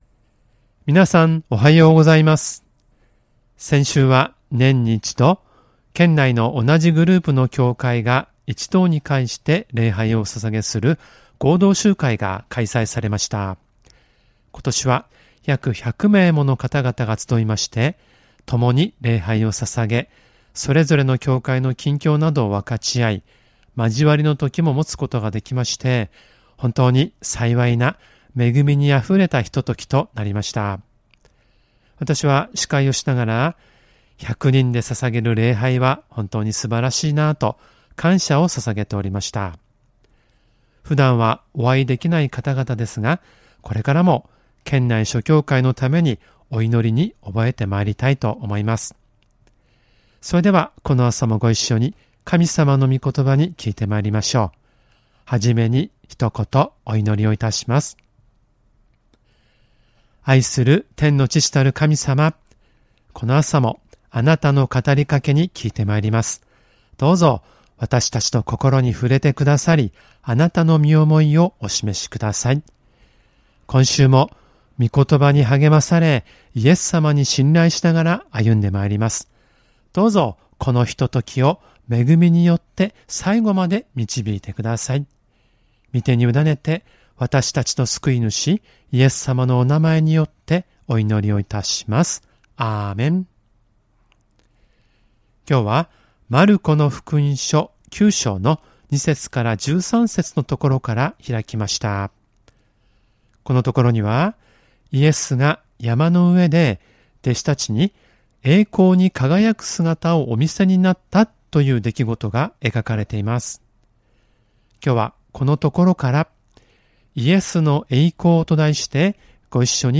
●主日礼拝メッセージ（赤文字をクリックするとメッセージが聴けます。MP3ファイル）